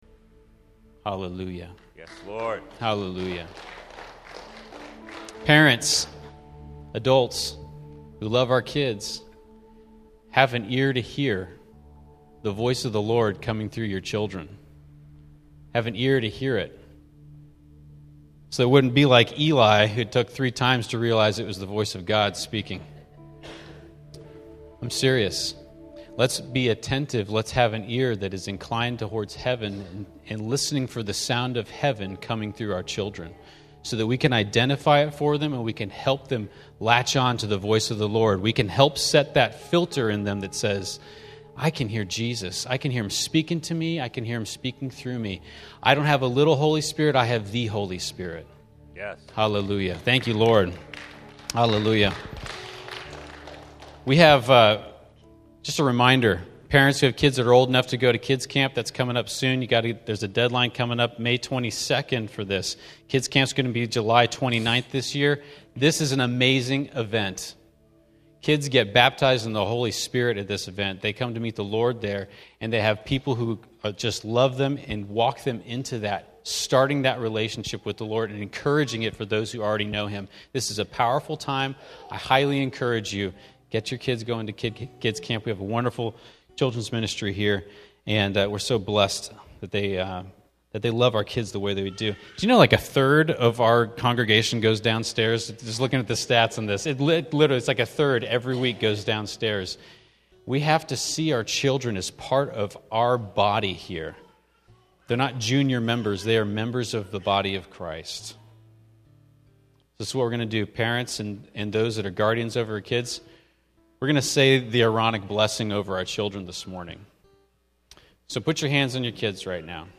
Encounter Service (Communion words)